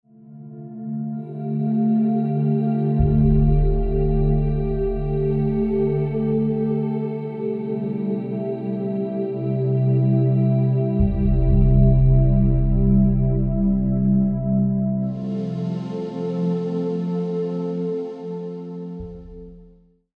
Meditation med musik, Meditation uden musik, Musik uden meditation – Titel: Stilhed, Pakke – alle udgaver af Stilhed